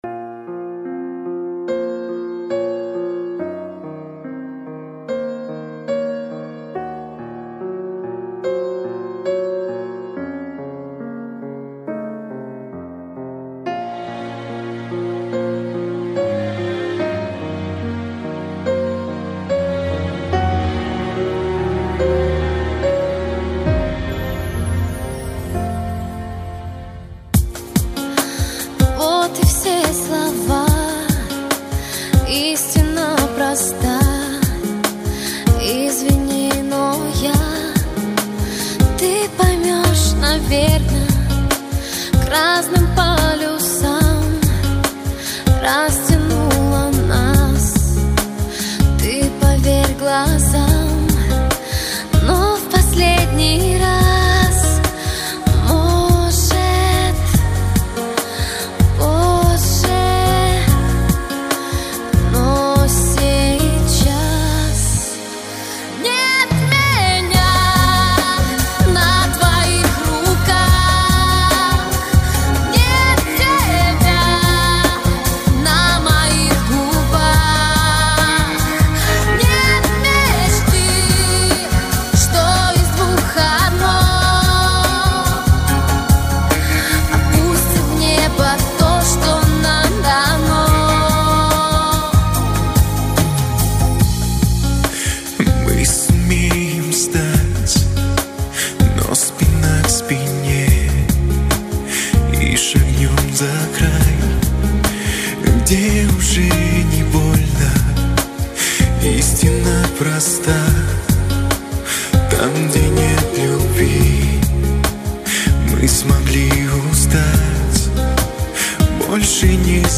krasivij_medljak____menja_na_tvoih_kah___tebja_na_moih_gubah_.mp3